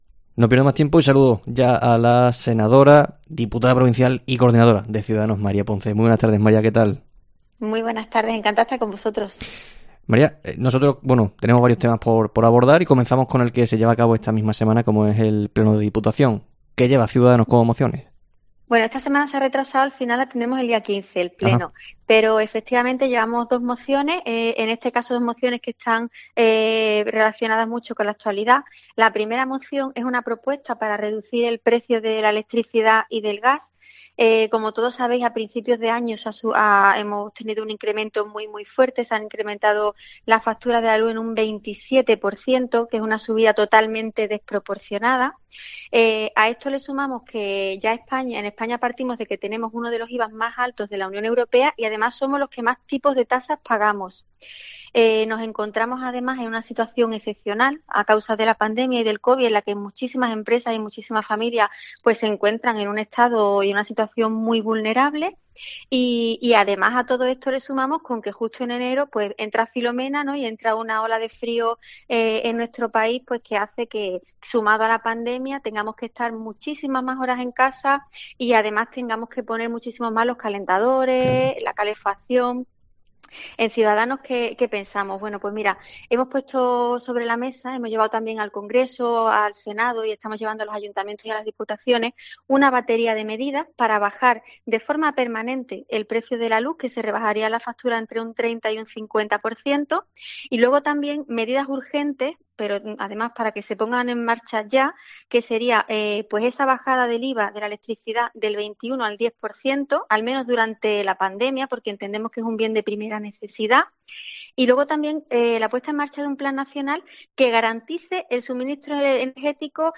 AUDIO: En este primer día de febrero hemos hablado con María Ponce, senadora, diputada provincial y coordinadora de Ciudadanos que nos ha analizado...
ENTREVISTA